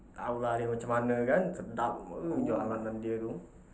Malay_Conversational_Speech_Data_by_Mobile_Phone